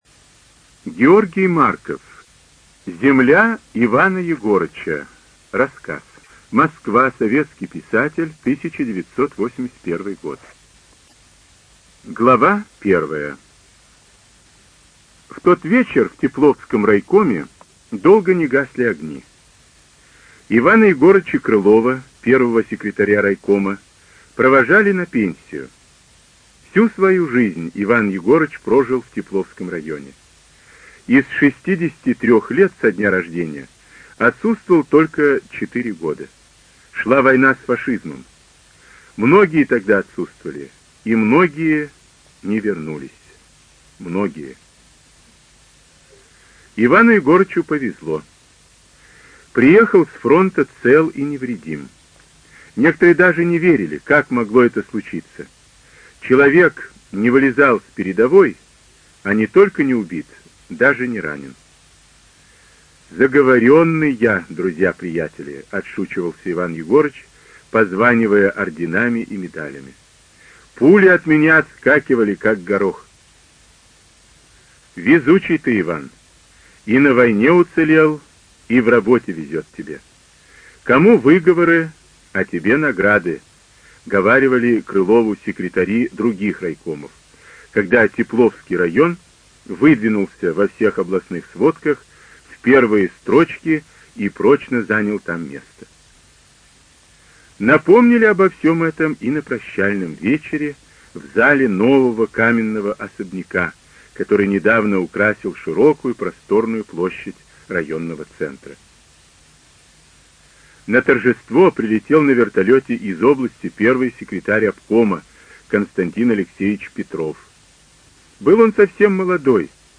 ЖанрСоветская проза
Студия звукозаписиЛогосвос